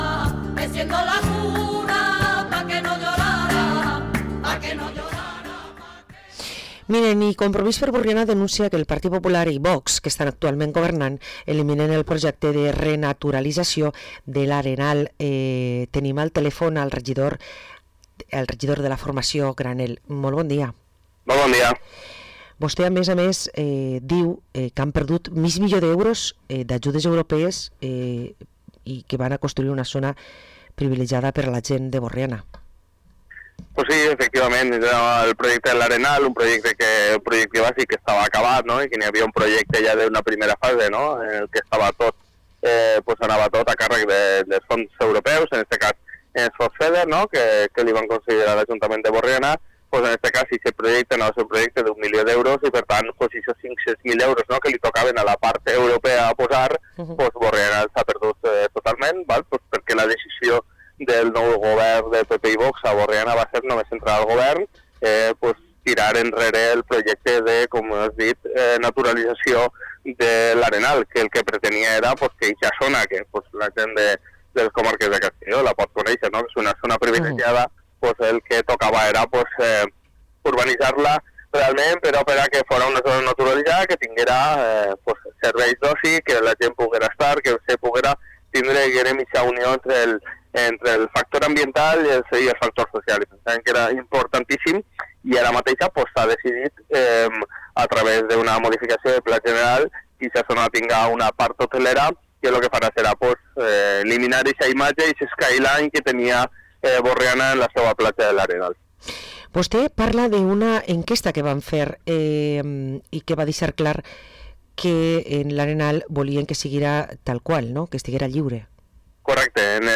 Parlem amb Vicent Granel, regidor de Compromís a l´Ajuntament de Burriana